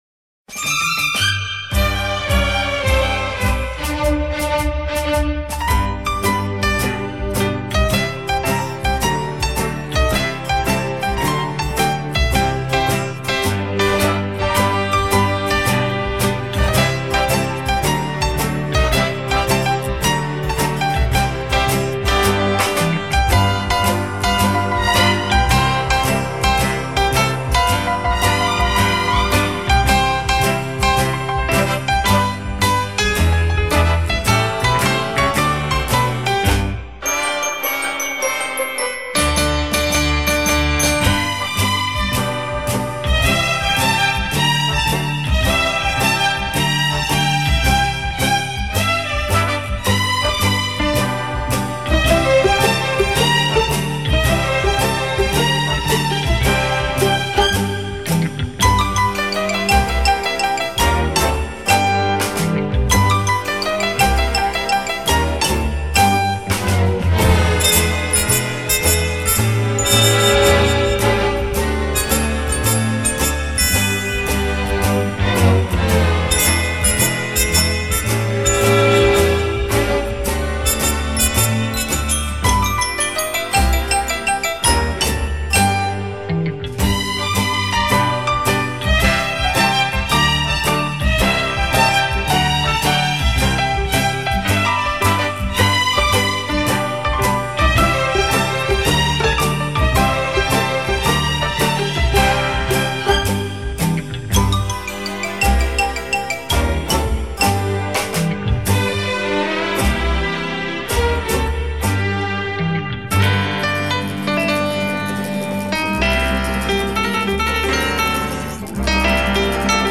Genre:Easy Listening,Instrumental